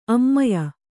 ♪ ammaya